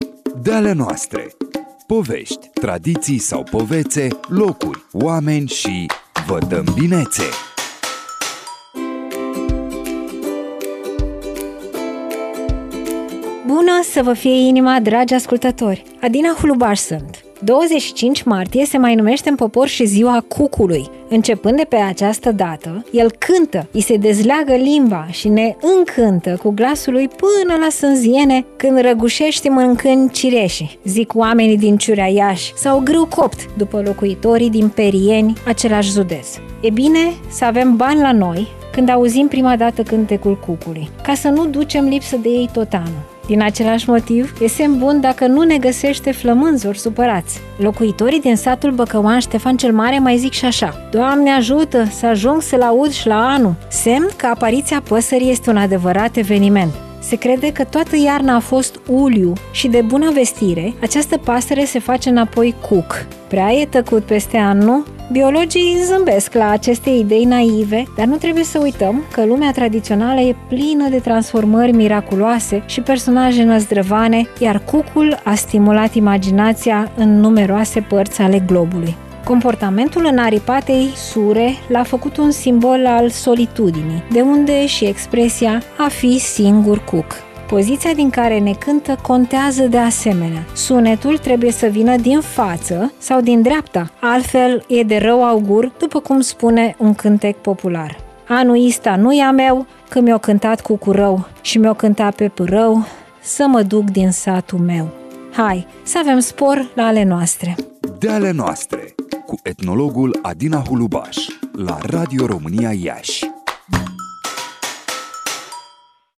De-ale noastre”, rubrică nouă în programele Radio România Iași!